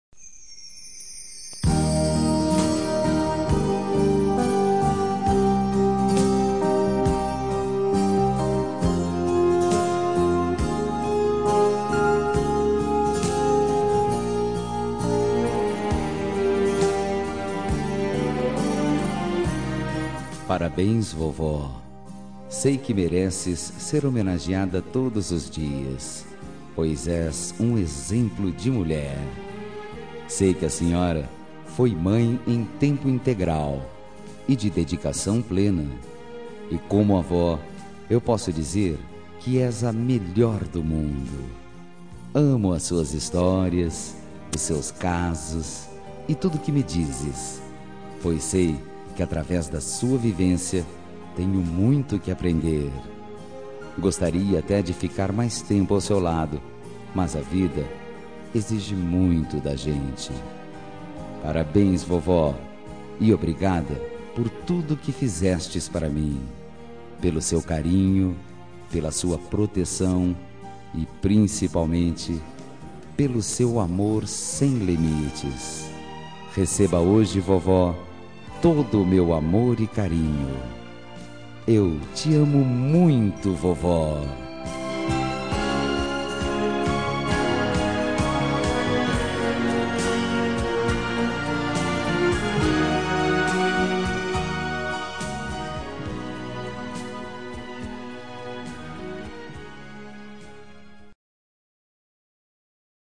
Telemensagem de Aniversário de Avó – Voz Masculina – Cód: 2074